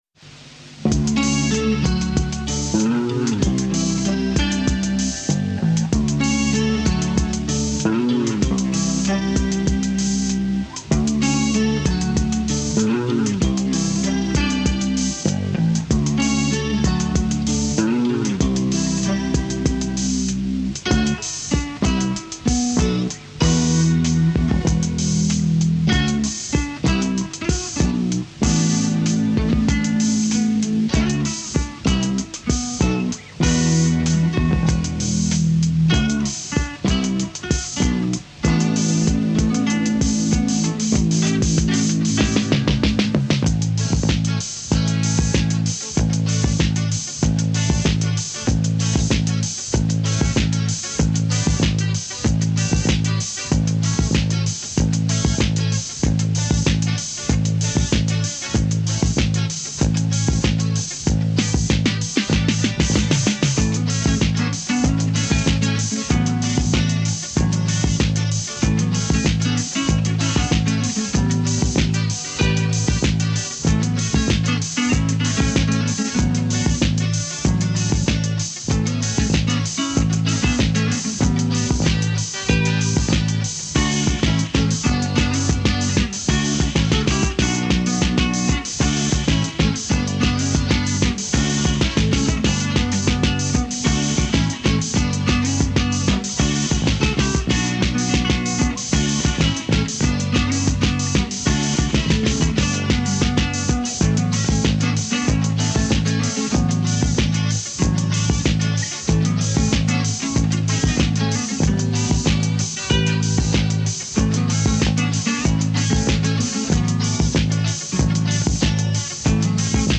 ４ｔｒカセット多重録音時代
しかし、ピアノ・シンセ等キーボード楽器を持っていなかったため、ギター＋ベース＋ドラム
インスト   *
Gutar
Bass